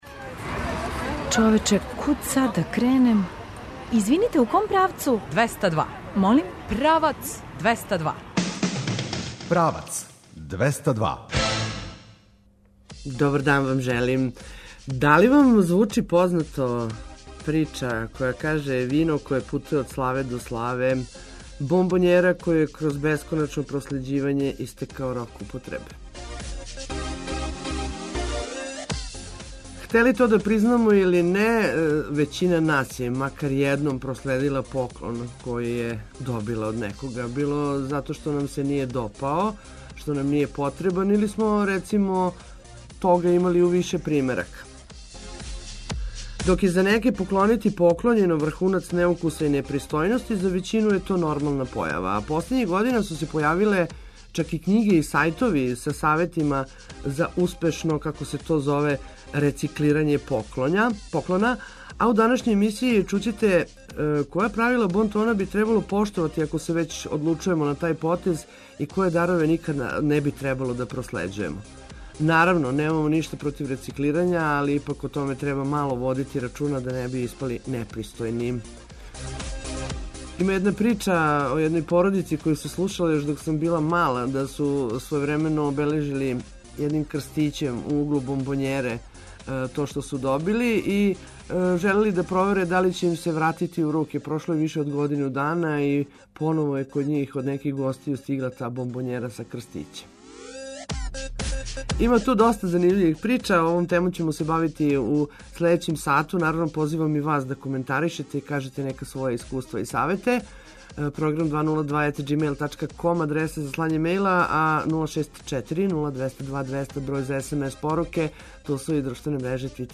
Наш репортер ће се јавити са београдског Трга републике који је претворен у атрактивно клизалиште. Очекују вас и најважније спортске вести.